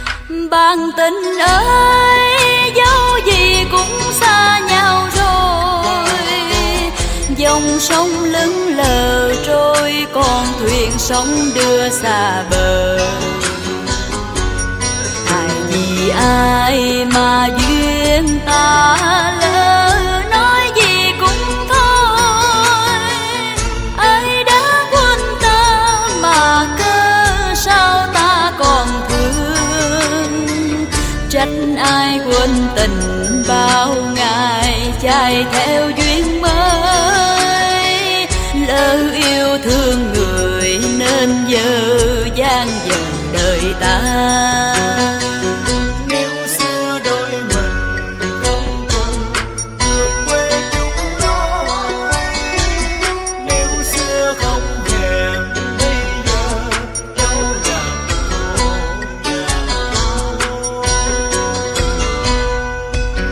Nhạc Bolero Trữ Tình